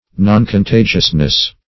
noncontagiousness - definition of noncontagiousness - synonyms, pronunciation, spelling from Free Dictionary